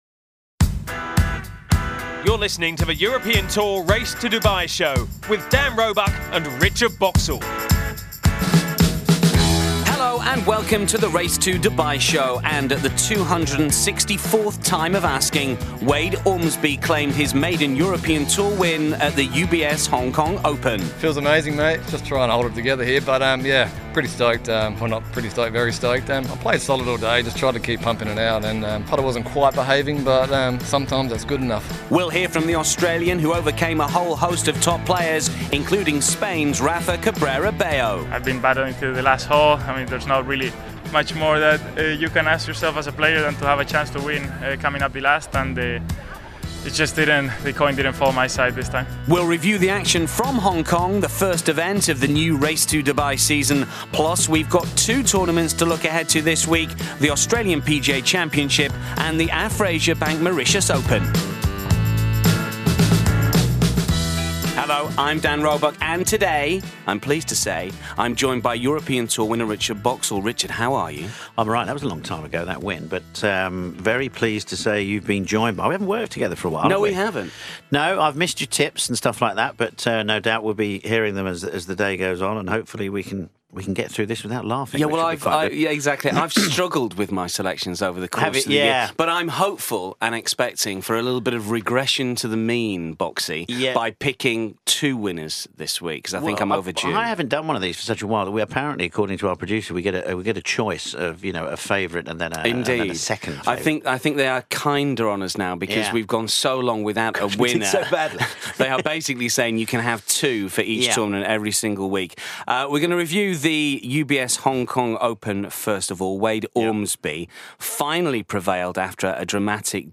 We hear from the Australian, from Rafa Cabrera Bello - who finished second at Fanling once again – as well as the new Race to Dubai champion Tommy Fleetwood, who was 6th.